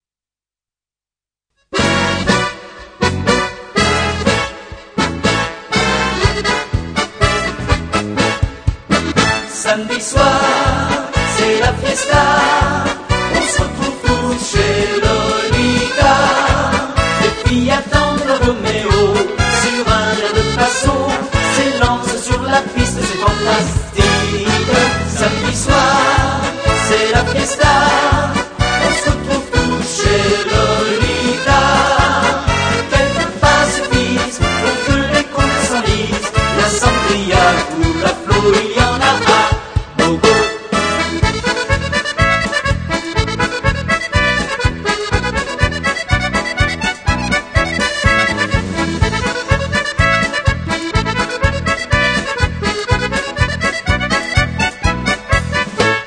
Titres enchain?s pour danser